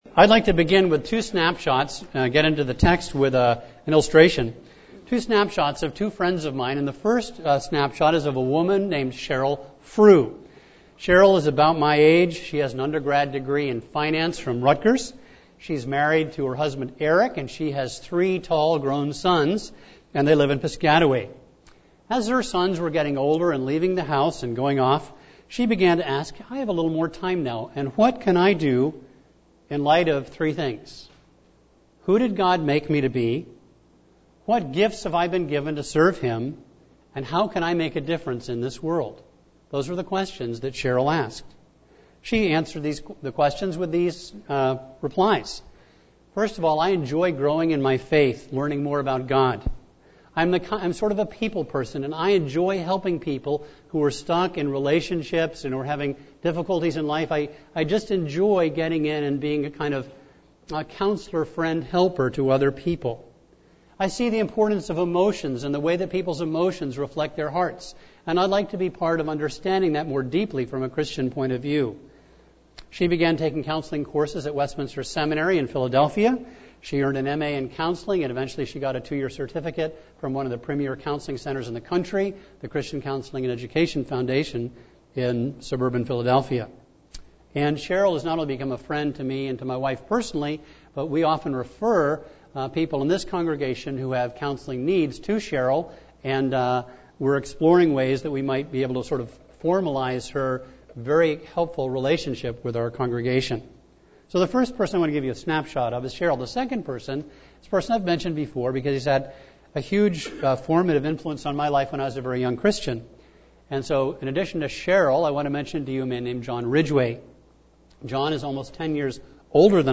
A message from the series "Ruth."